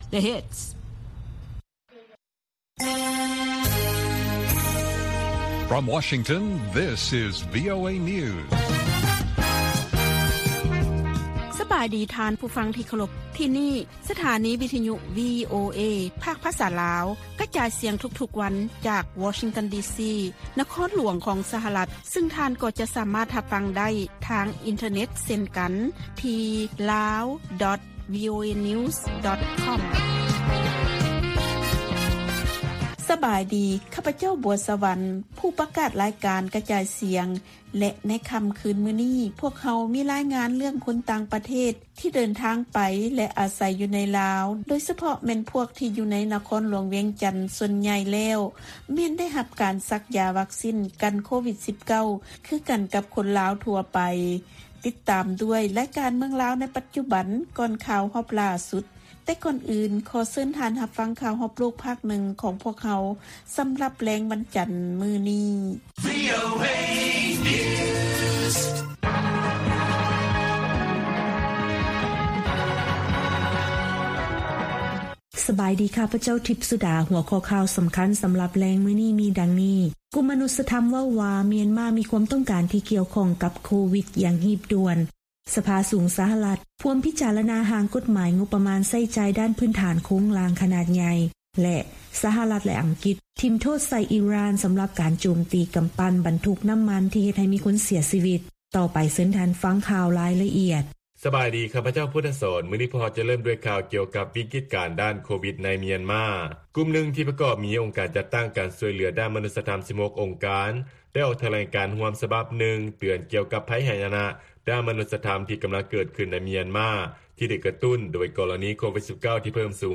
ວີໂອເອພາກພາສາລາວ ກະຈາຍສຽງທຸກໆວັນ. ຫົວຂໍ້ຂ່າວສໍາຄັນໃນມື້ນີ້ມີ: 1) ຊາວລາວ ແລະຊາວຕ່າງປະເທດທີ່ອາໄສຢູ່ໃນນະຄອນຫຼວງ ຕ່າງກໍໄດ້ສັກຢາວັກຊີນ ກັນໂຄວິດ-19 ໂດຍບໍ່ຖືກຈຳແນກ.